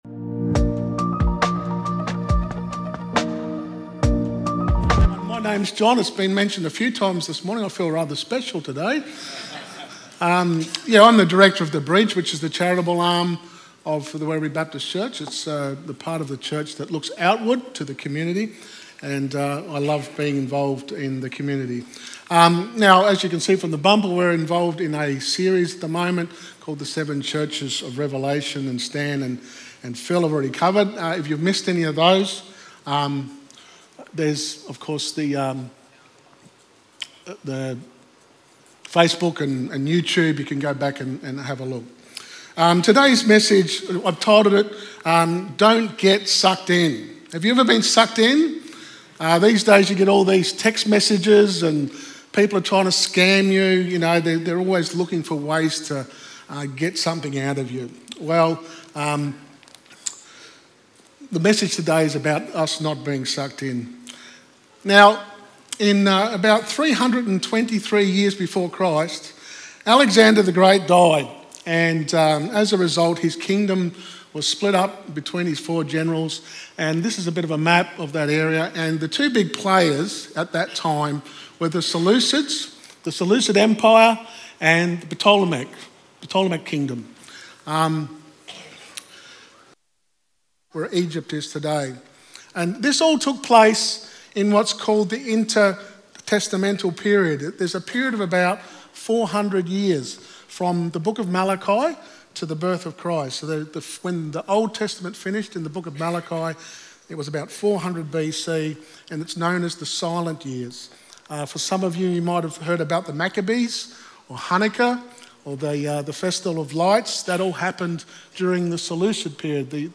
Guest Speaker
Download Download Sermon Notes 7Churches_Study-5May.pdf Ever find yourself pulled in all directions?